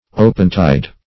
Opetide \Ope"tide`\, n. [Ope + tide.]